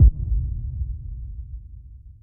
001-boomkick.wav